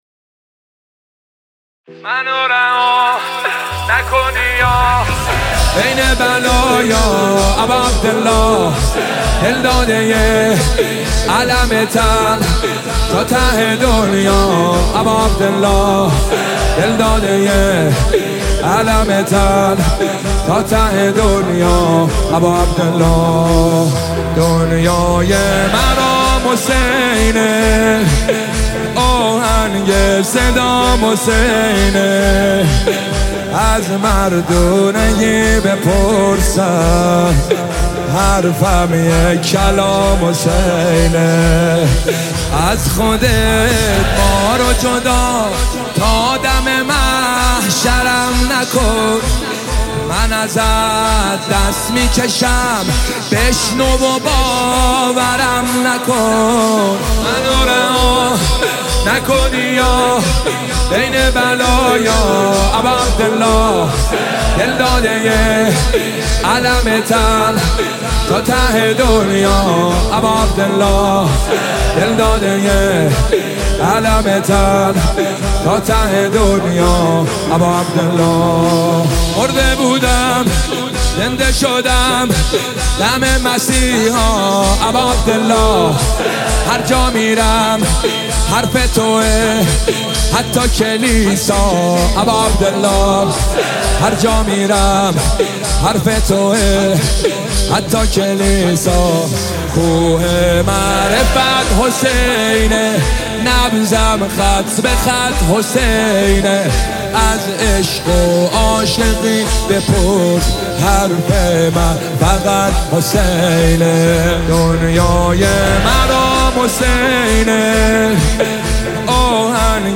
نماهنگ دلنشین
مداحی